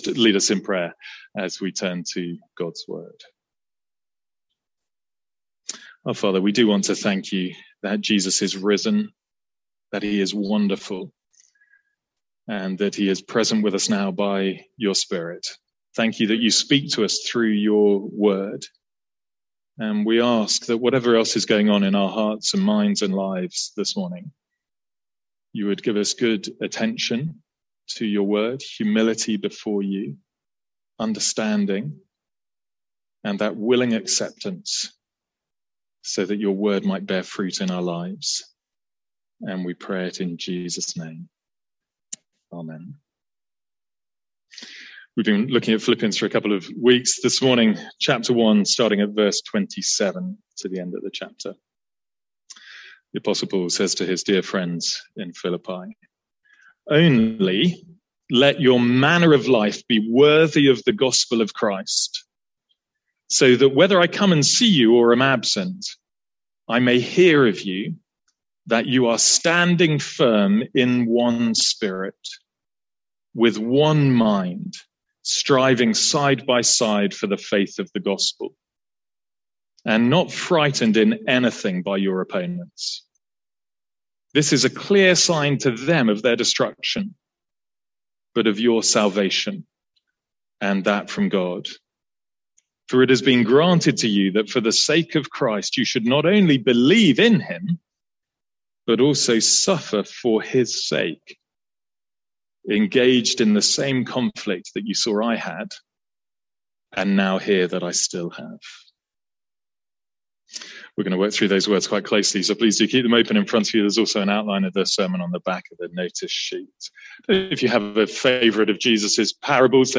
Sermons | St Andrews Free Church
From our morning series in Philippians